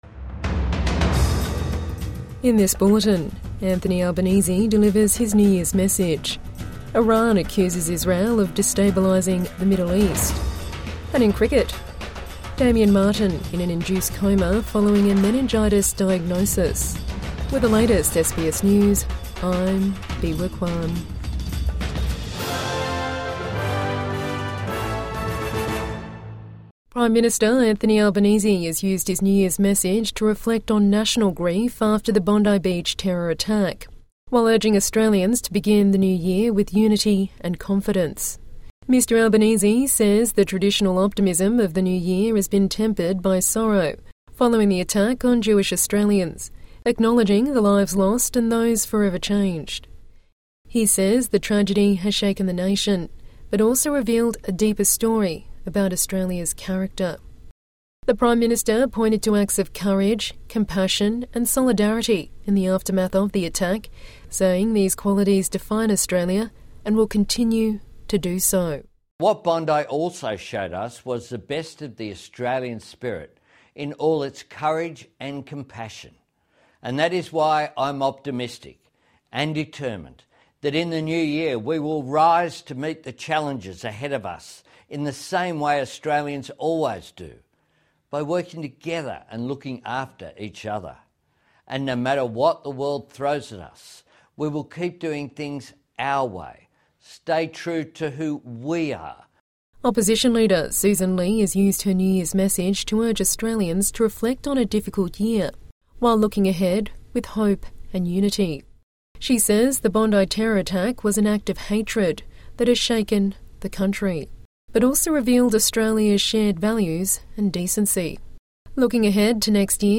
Anthony Albanese delivers his New Year's message | Midday News Bulletin 31 December 2025